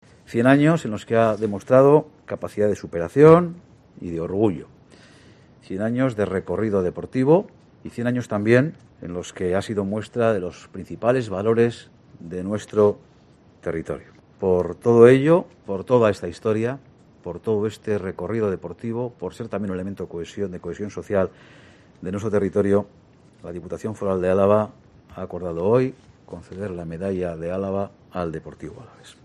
Ramiro GOnzález, diputado general de Álava